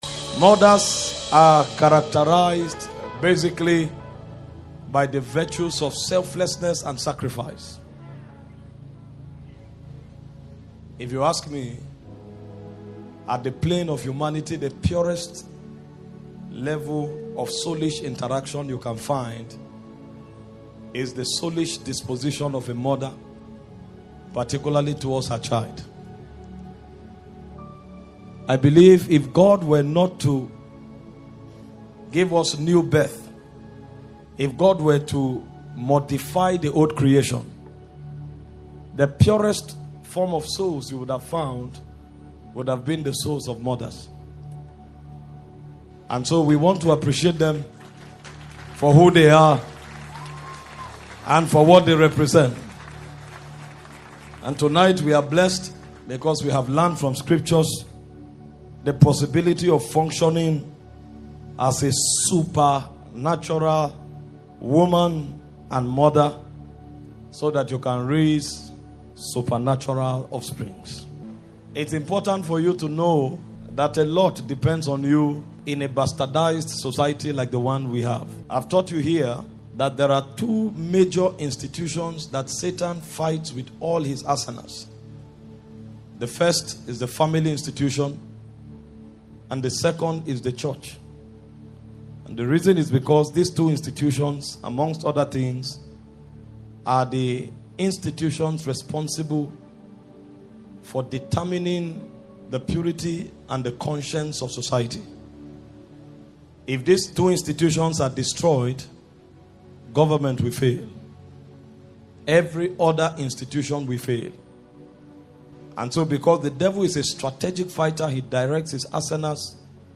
[Sermon]